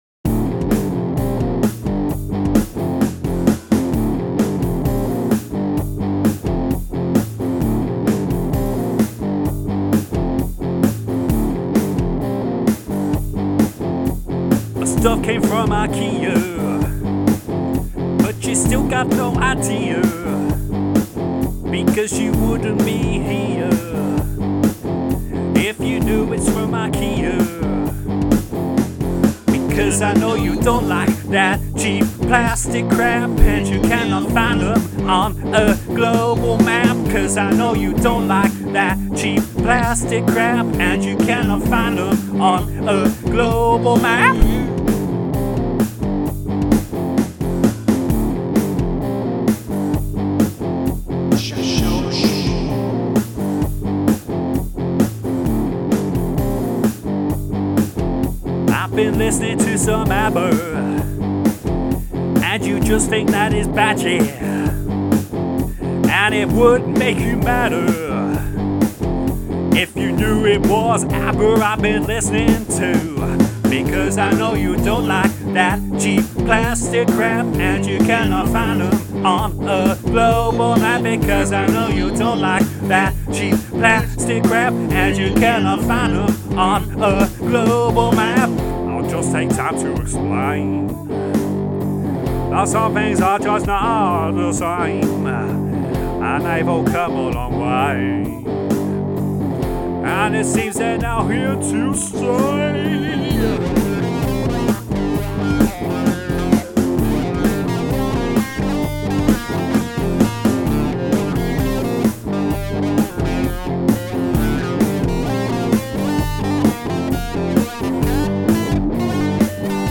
What a riff!